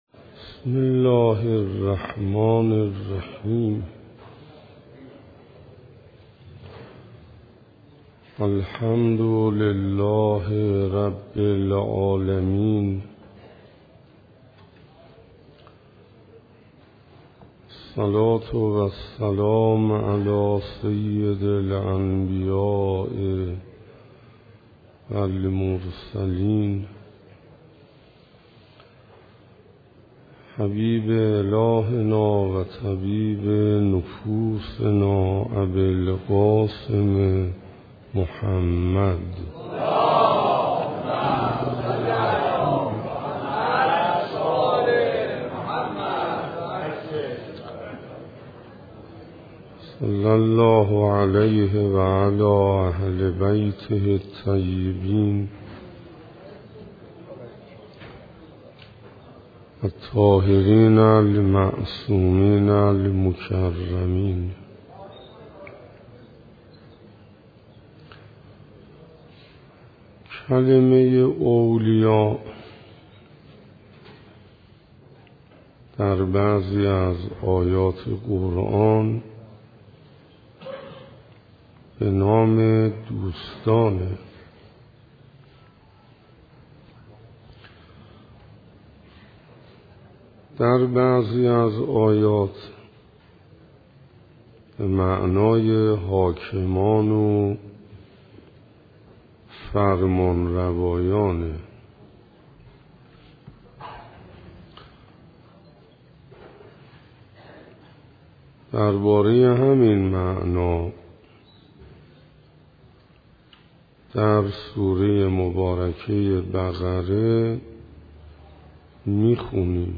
معارف اسلامی - روز سوم - محرم 1437 - مسجد هدایت بازار -